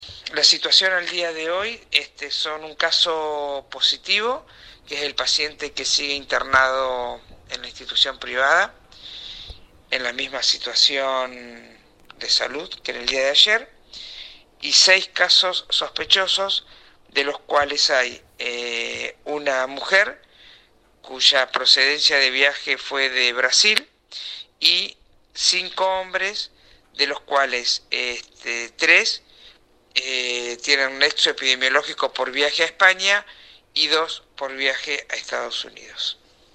Audio | La Secretaria de Salud de la MGP Dra. Viviana Bernabei da el parte sanitario de hoy, 19 de marzo 2020.